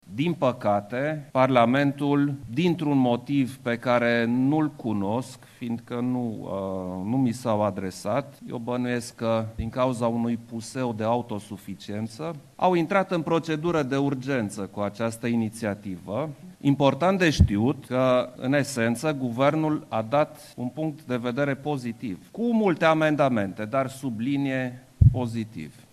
Dezbatere pe tema taxei radio-tv,  recent eliminate de Parlament, la pachet cu altele.
Președintele Klaus Iohannis, prezent la discuții, insistă că această taxă nu ar fi trebuit să se regăsească printre cele 102 – eliminate de legea denumită ”legea Dragnea”.